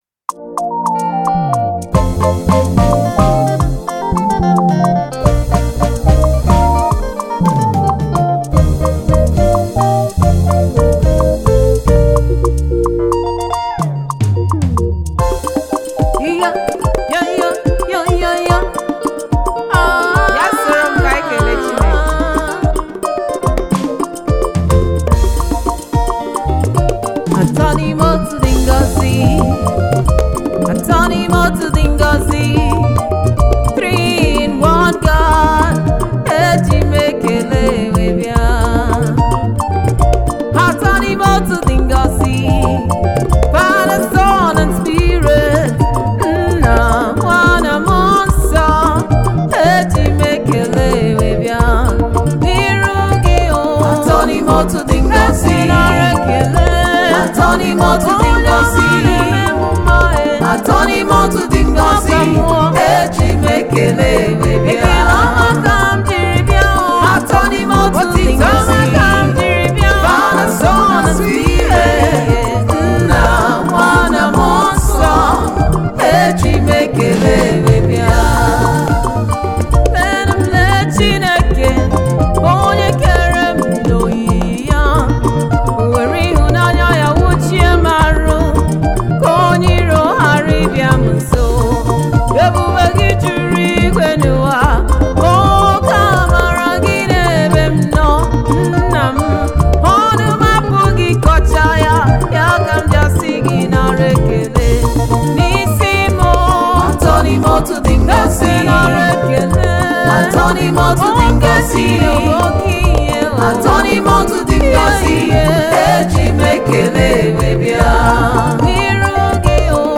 African praisemusic
thanksgiving praise song